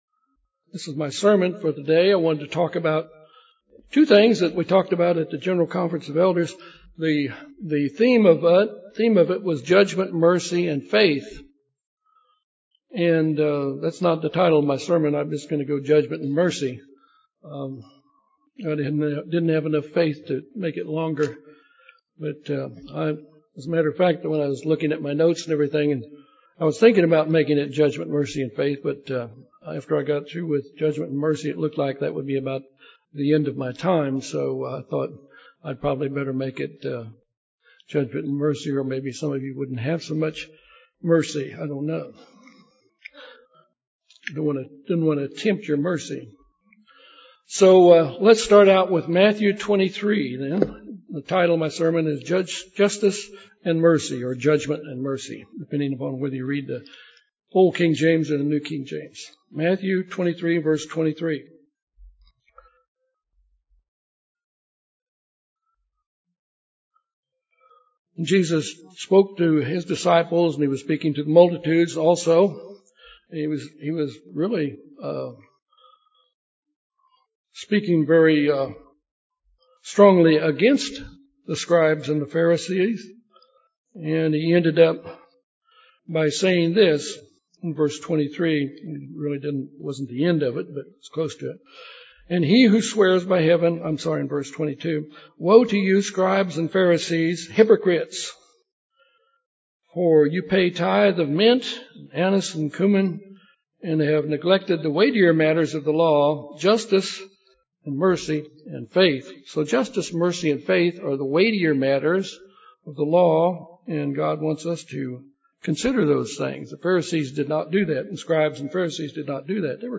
This sermon deals with our need to follow Christ's example of judging righteously and showing mercy. We will be doing this in His Kingdom.